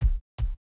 sound / monster / orc_black / foot.wav
foot.wav